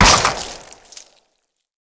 fx_explosion_collar.wav